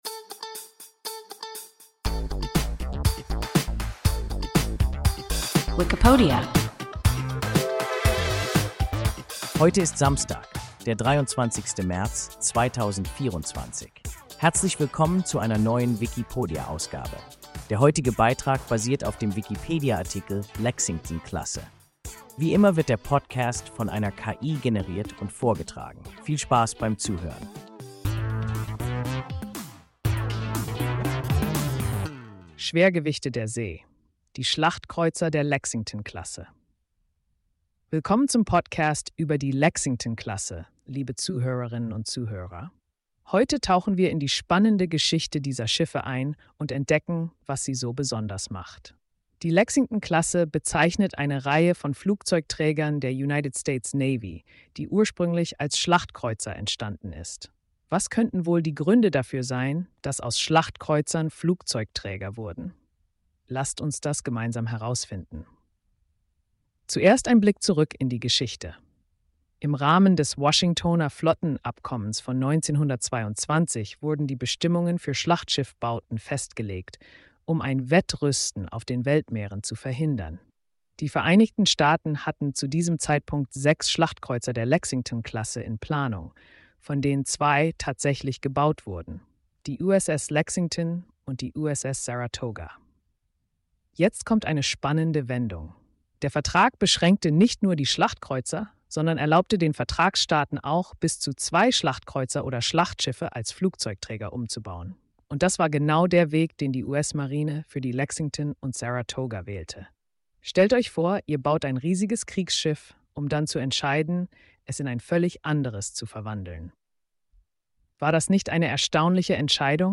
Lexington-Klasse – WIKIPODIA – ein KI Podcast